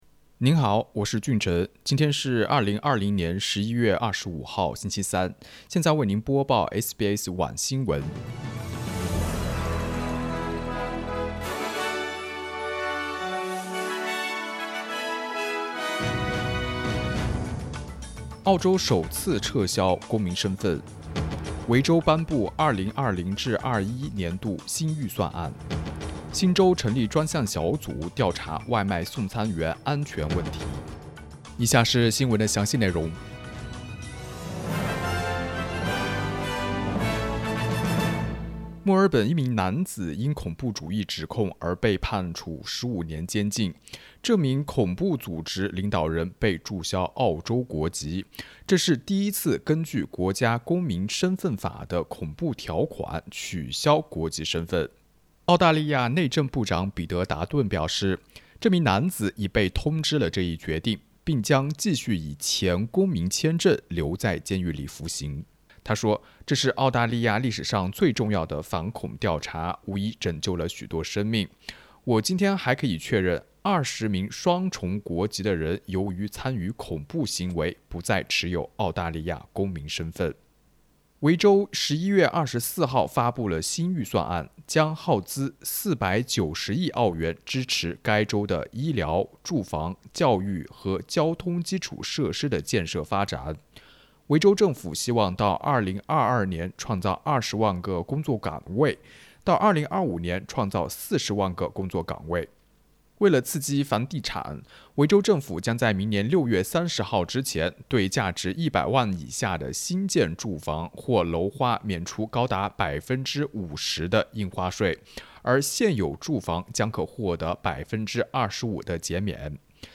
SBS Mandarin evening news Source: Getty Images
mandarin_evening_news_1125.mp3